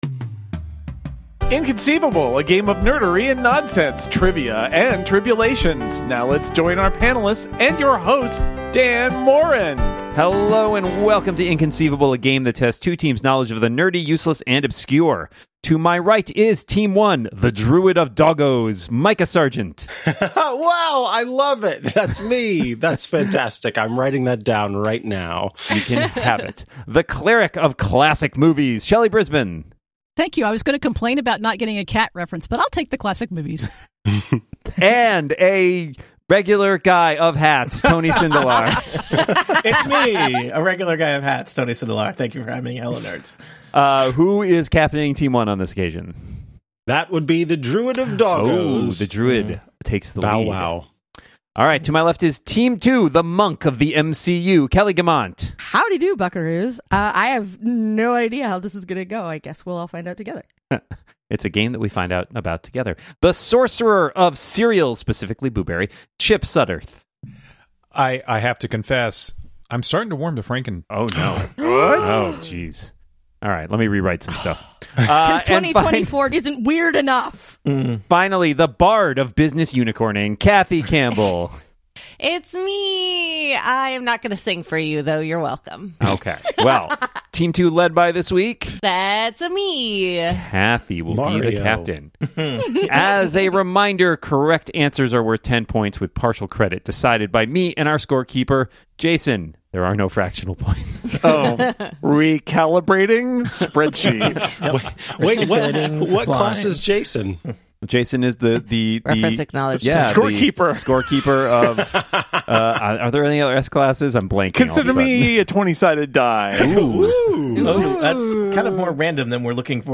Each episode, two teams face off to answer diabolical questions about TV shows, comics, movies, books, games, and more.
Welcome back to “Inconceivable!”, the show that tests two teams’ knowledge of the nerdy, the useless, and the obscure.